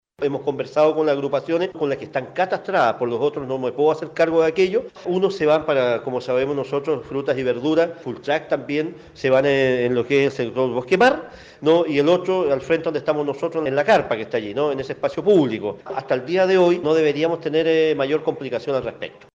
El alcalde de Puerto Montt, Gervoy Paredes, aseguró que no deberían tener mayores problemas para el traslado de vendedores ambulantes que están bajo catastro.